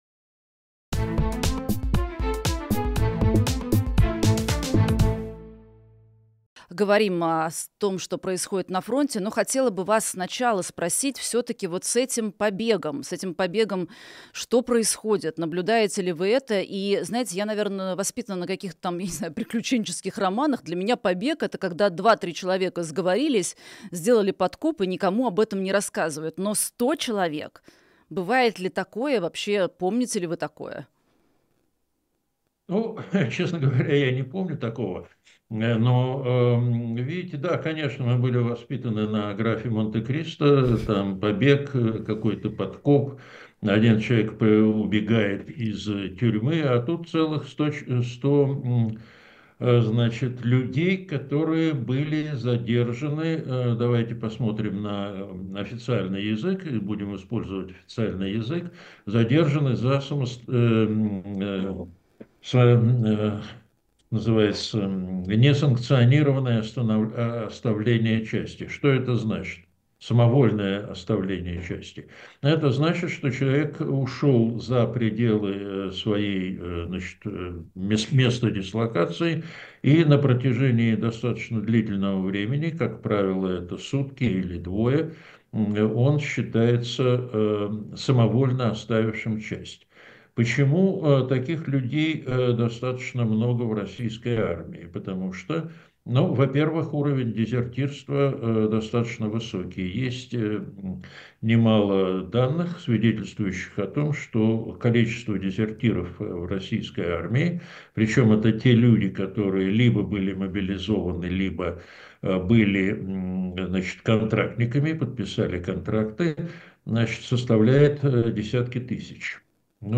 Фрагмент программы от 19.04.2025
ведущая новостей
военно-политический эксперт